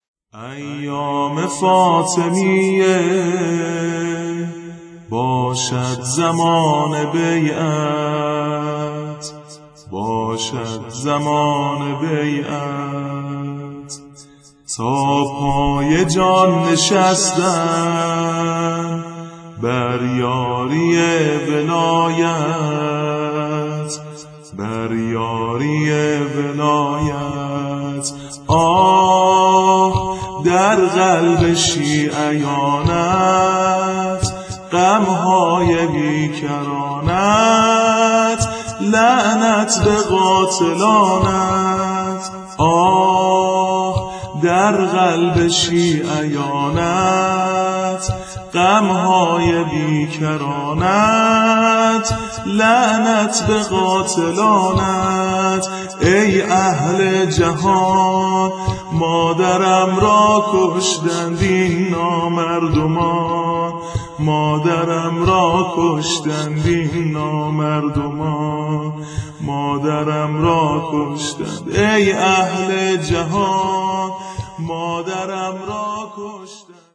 نوحه سينه زني با سبك براي شهادت حضرت موسي كاظم - (با حلقه های زنجیر مأنوس اشک و آهم)